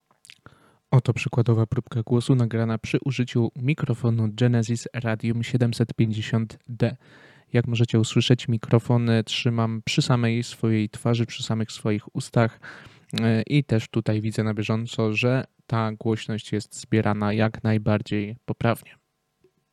Genesis Radium 750 to mikrofon dynamiczny, jednokierunkowy, czyli kardioidalny.
Sam dźwięk przechwytywany przez Radium 750D jest czysty.
Nasz głos jest ponad wyraźny, a otoczenie praktycznie nieprzechwytywane.
Przykładowe próbki głosu prezentuje się następująco: